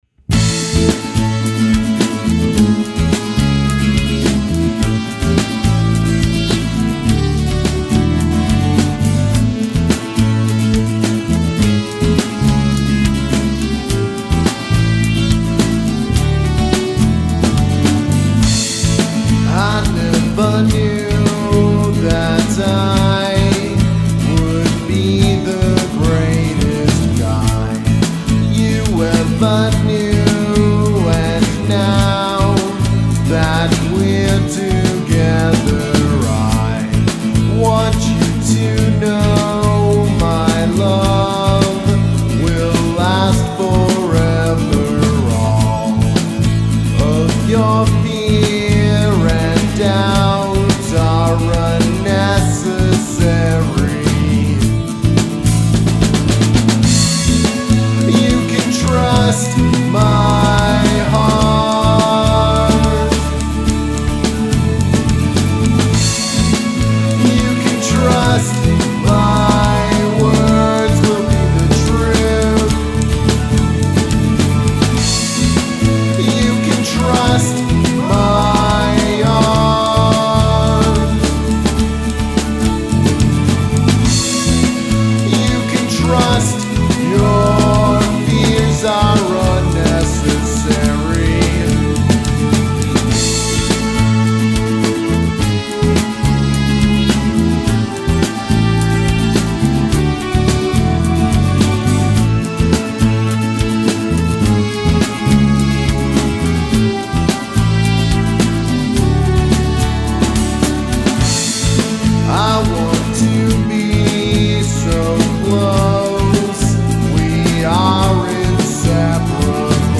Psychedelic